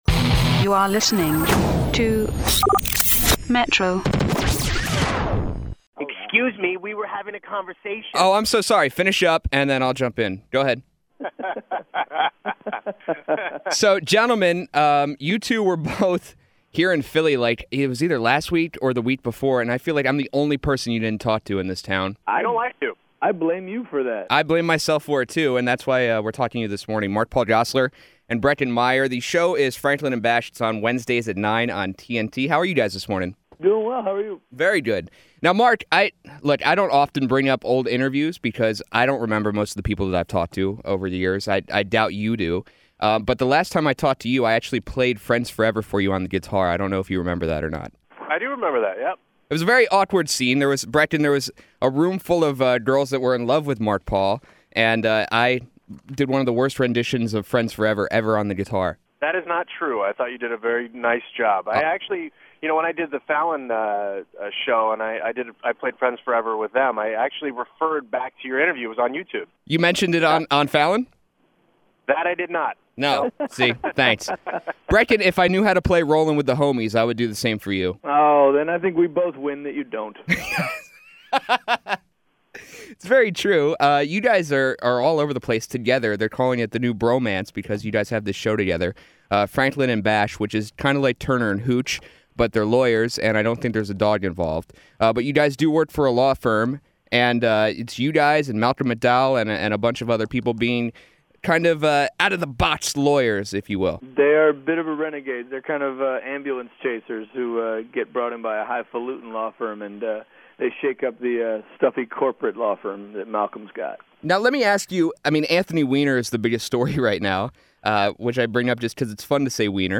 After missing their media storm for “Franklin And Bash” last month, I took TNT up on the opportunity to speak with Mark Paul Gosselaar and Breckin Meyer this morning. The only time you’ll hear Garfield, Anthony Weiner, and “Friends Forever” discussed all in one place.